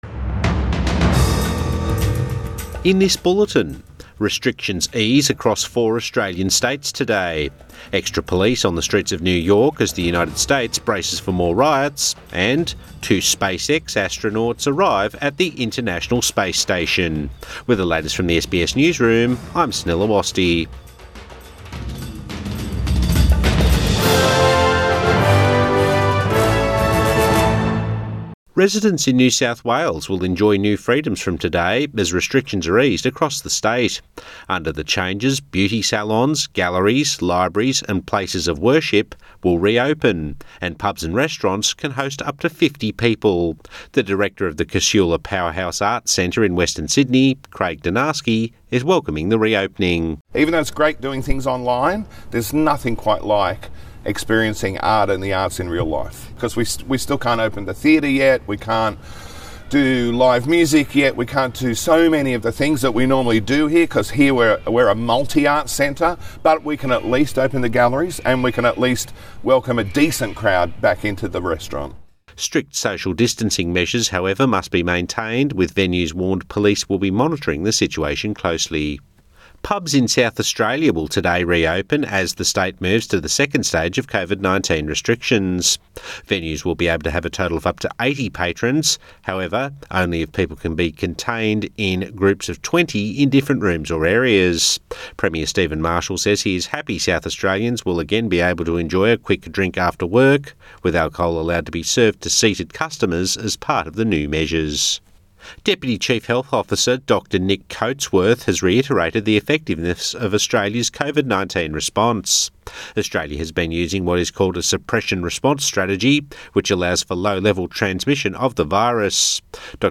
AM Bulletin 1 June 2020